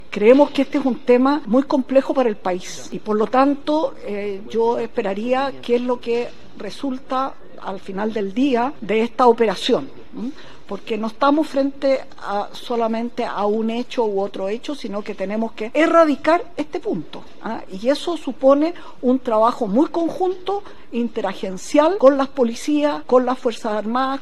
Delpiano anunció que, ante la gravedad de la situación, el Ejército inició este jueves un operativo de fiscalización en todos los cuarteles de la zona norte, incluyendo revisión de lockers y aplicación de test de drogas al personal de turno.